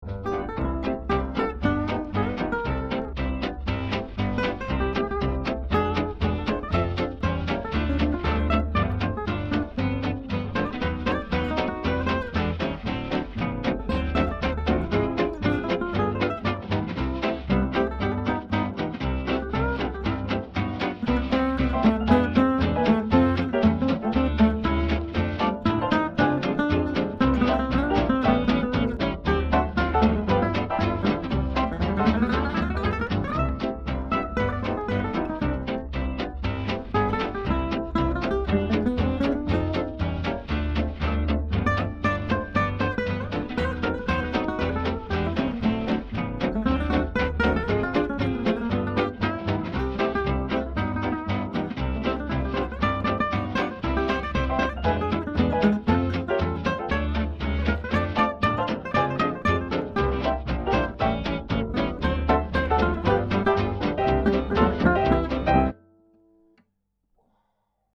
Improviser en jazz manouche | Méthode pour guitare
Cours en ligne afin d'apprendre à improviser dans le style jazz manouche à la guitare.